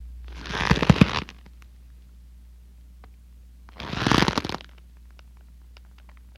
Leather Twists x2